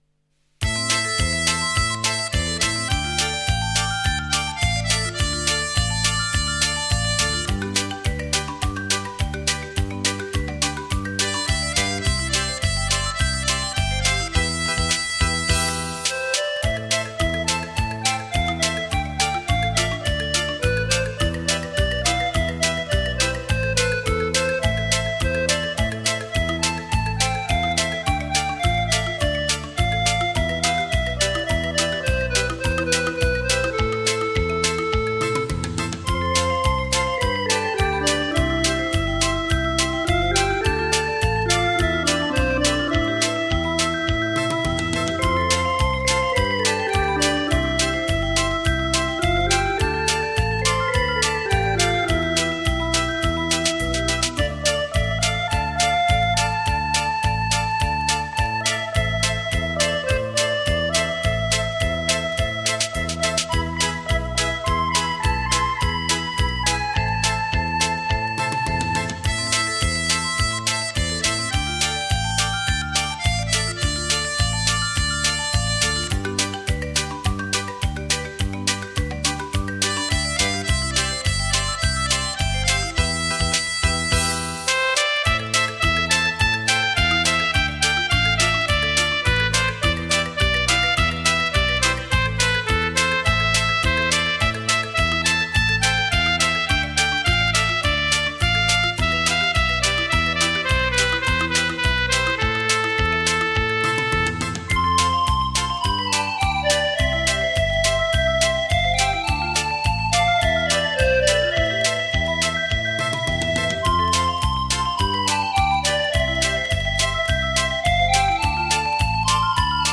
电子琴演奏